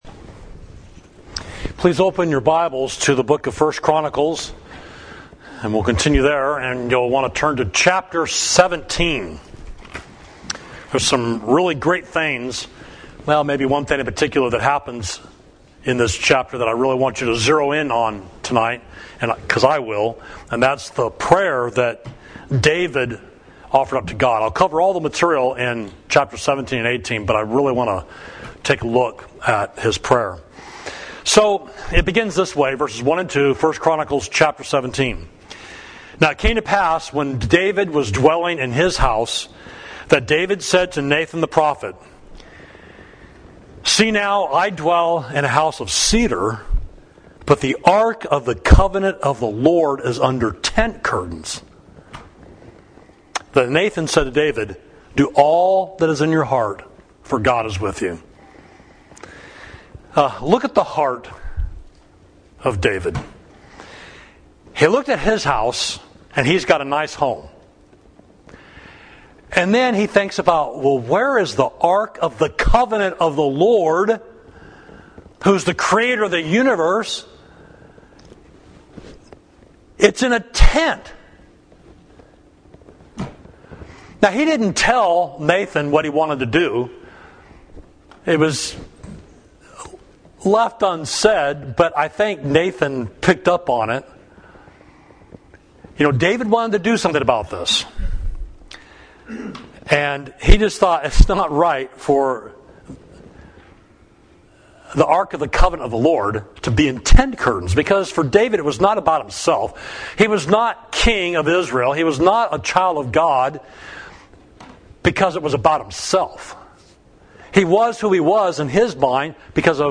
Sermon: How to Pray to God